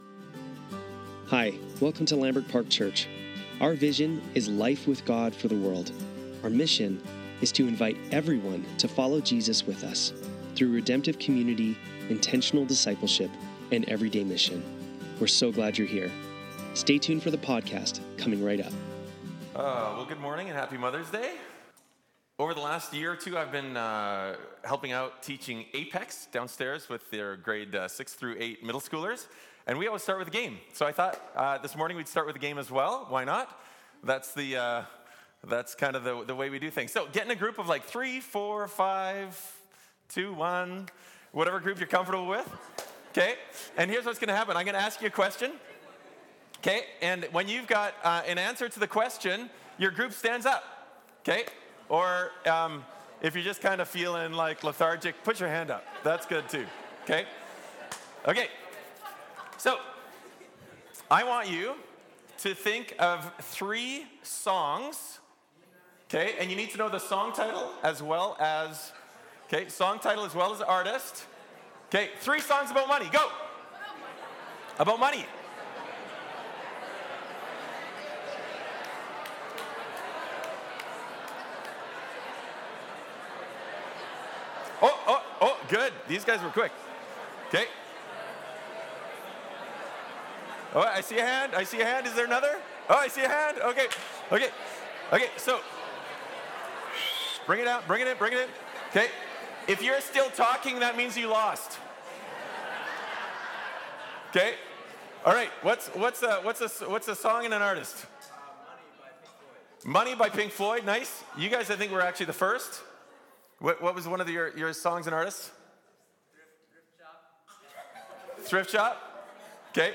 Lambrick Sermons | Lambrick Park Church